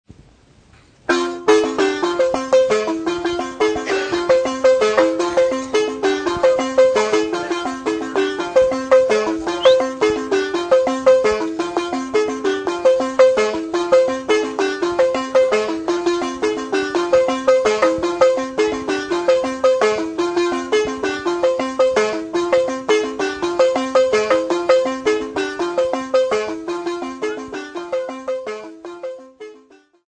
Folk music--Africa
Field recordings
Africa Zambia Not specified f-za
sound recording-musical